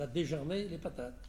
Localisation Saint-Christophe-du-Ligneron
Catégorie Locution